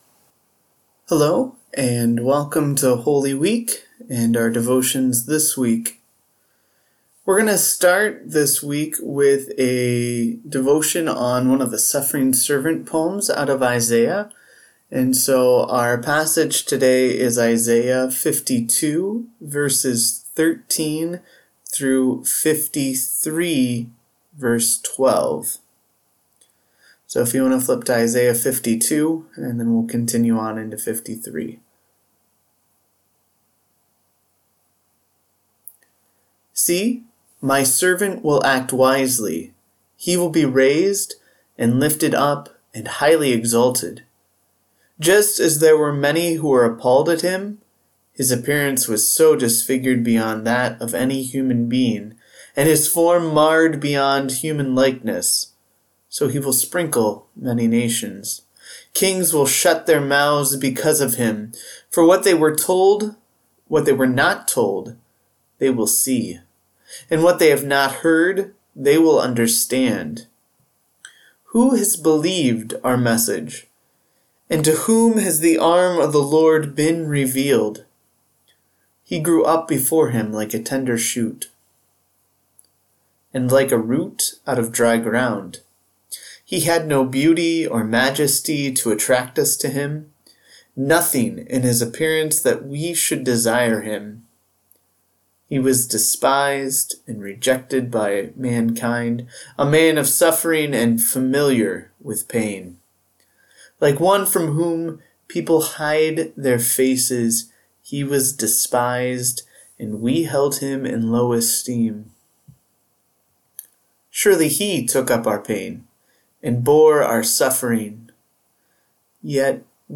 The Suffering Servant – Devotional